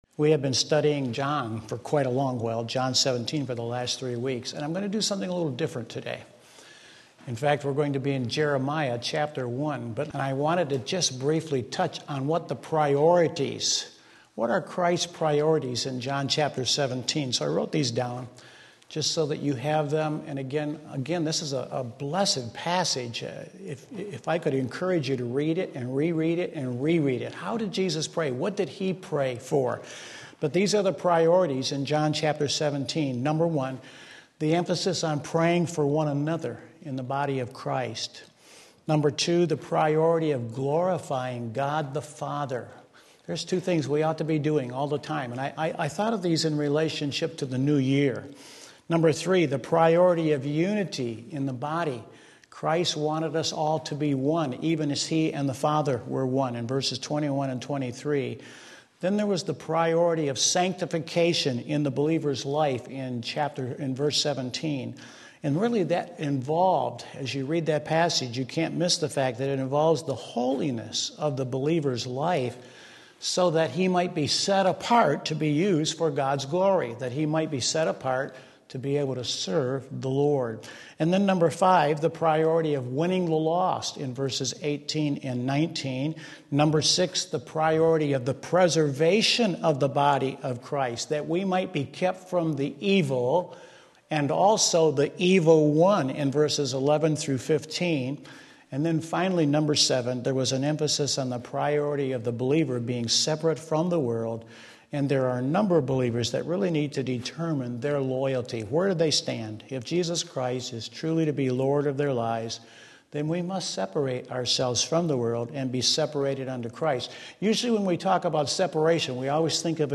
Sermon Link